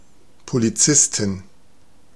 Ääntäminen
Synonyymit keuf policier bœuf argousin bourrin lardu dular Ääntäminen France (Île-de-France): IPA: /flik/ Paris: IPA: [flik] Haettu sana löytyi näillä lähdekielillä: ranska Käännös Konteksti Ääninäyte Substantiivit 1.